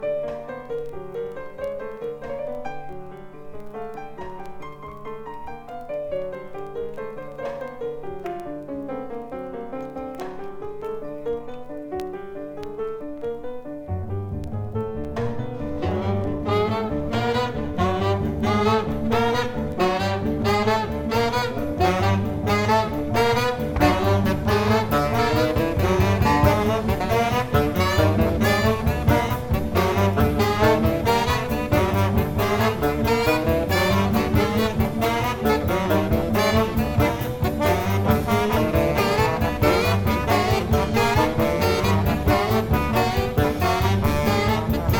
スリリングと閃き、エモーショナルな瞬間も訪れる怒涛な演奏が最高です。